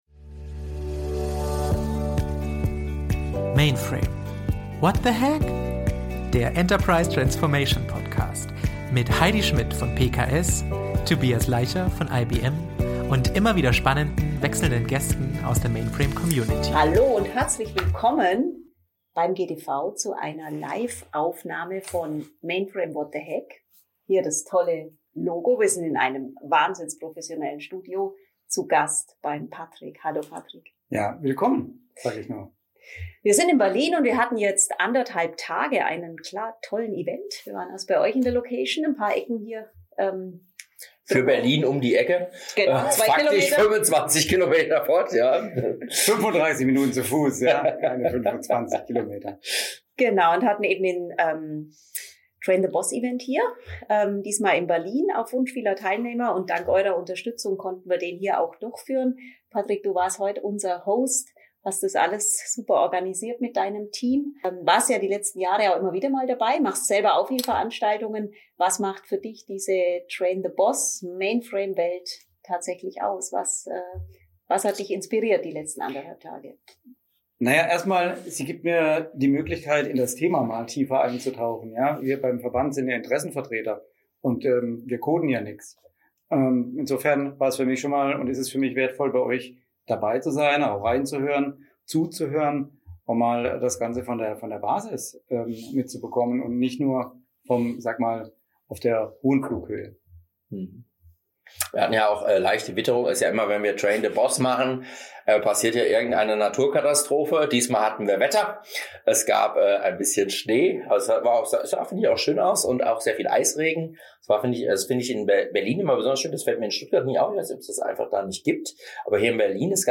Live-Folge #92 aus Berlin